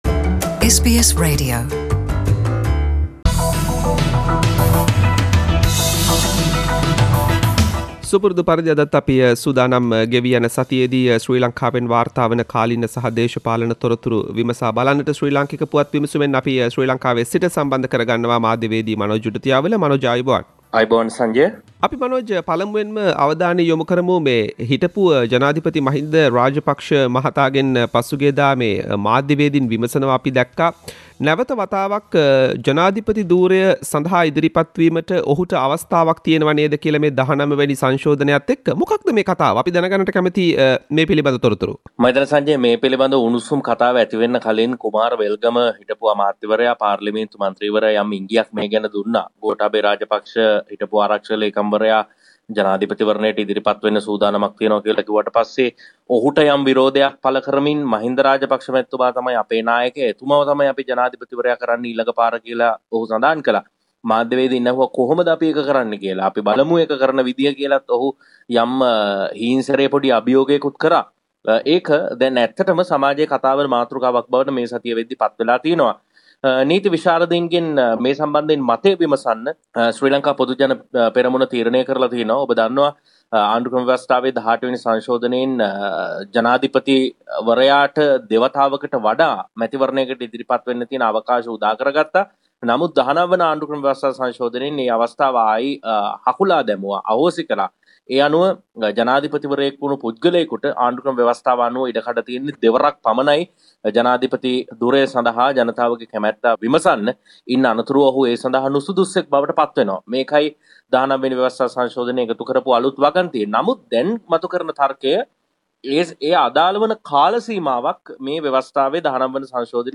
ශ්‍රී ලංකාවේ සිට වාර්තා කරයි....